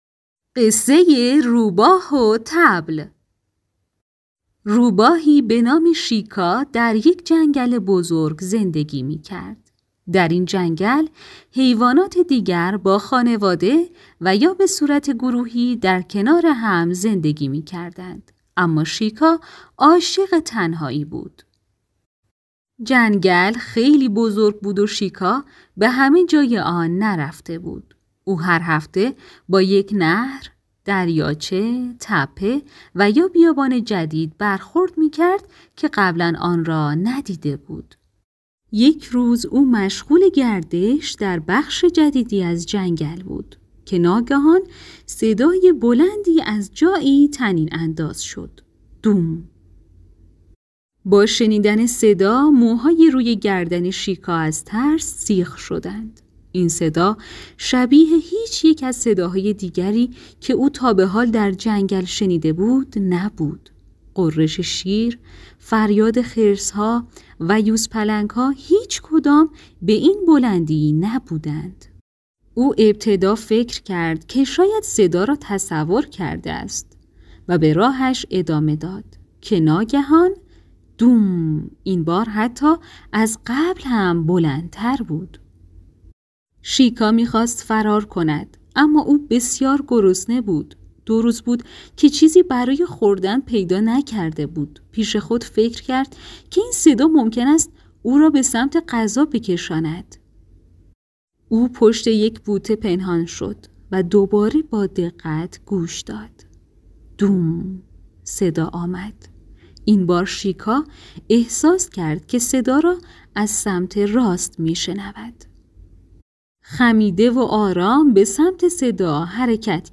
قصه های کودکانه